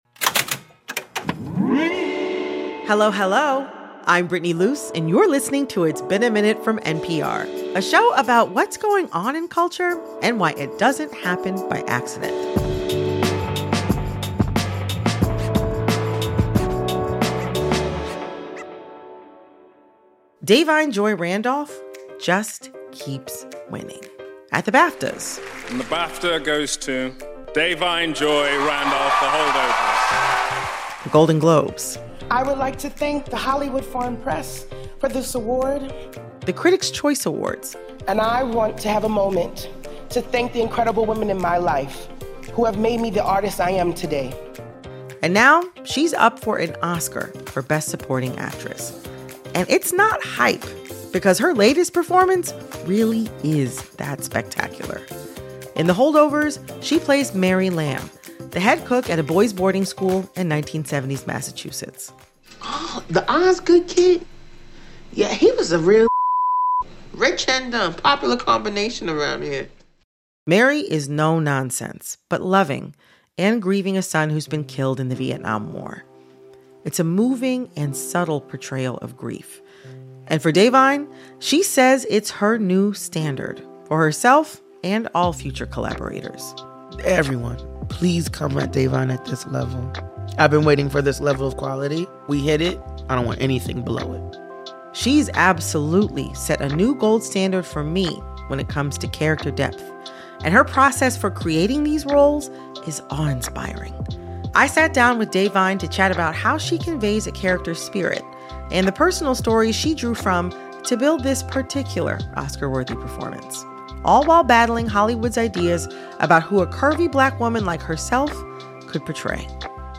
Host Brittany Luse sat down with Da'Vine to chat about how she conveys a character's spirit – and the personal stories she drew from to build this particula